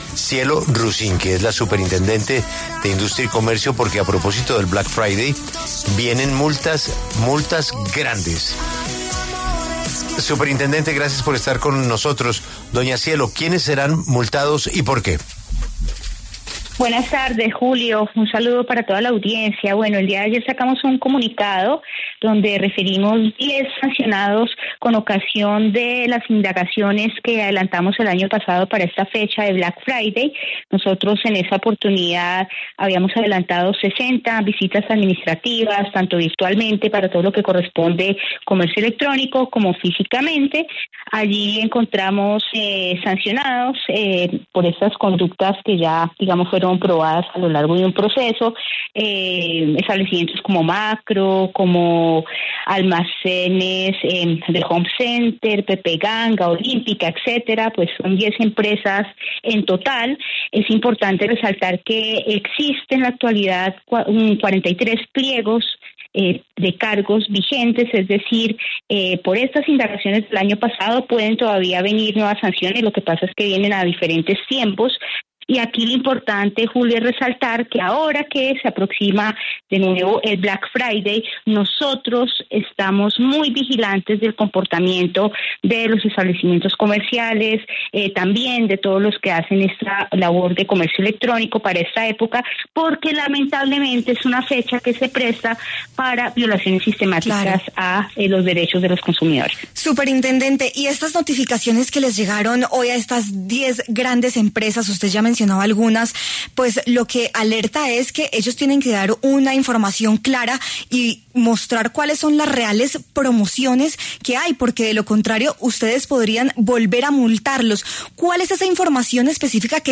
Cielo Rusinque, superintendente de Industria y Comercio, pasó por los micrófonos de La W para hablar sobre el tema, ya que el país está a puertas del Black Friday del 28 de noviembre.